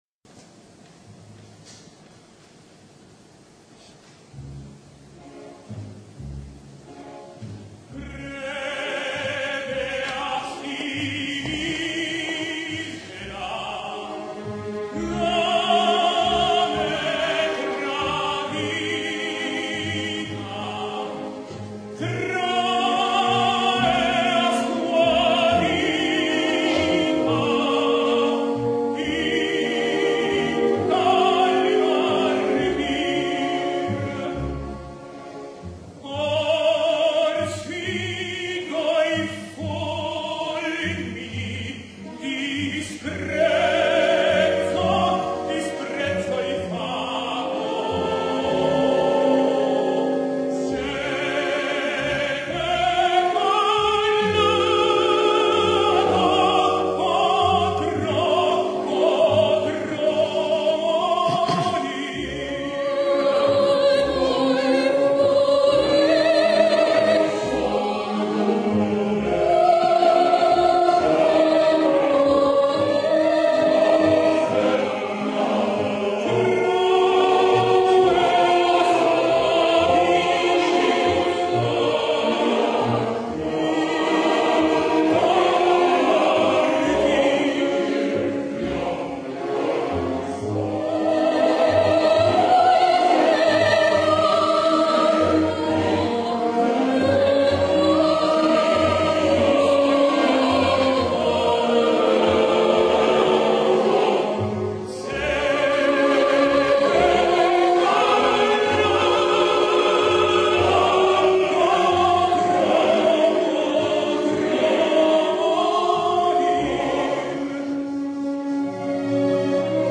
Celso Albelo sings I puritani: